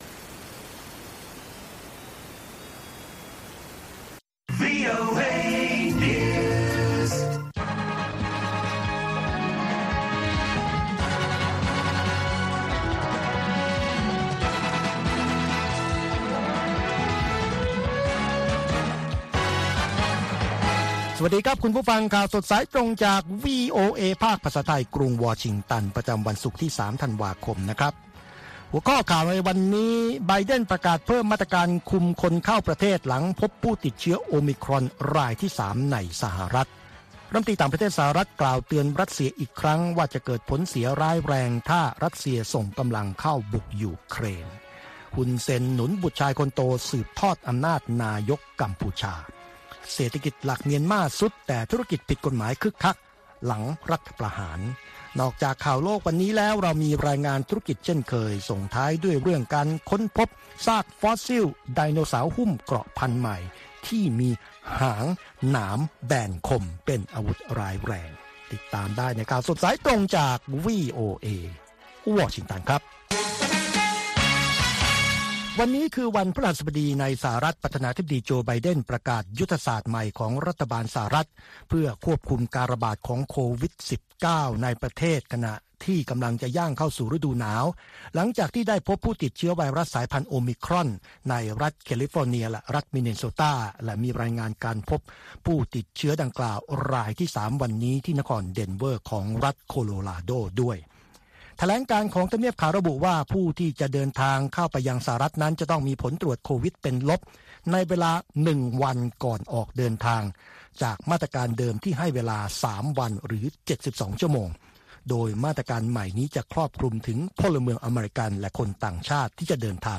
ข่าวสดสายตรงจากวีโอเอ ภาคภาษาไทย ประจำวันศุกร์ที่ 3 ธันวาคม 2564 ตามเวลาประเทศไทย